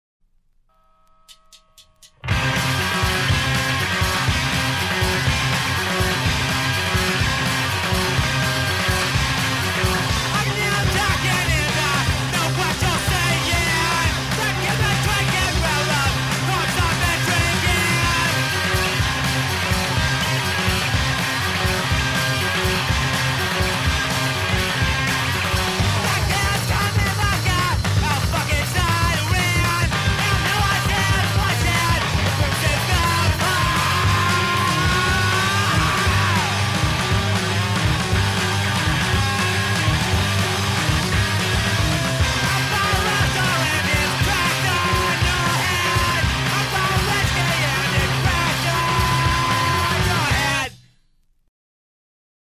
Studio album
Genre Electronica
New wave
Punk rock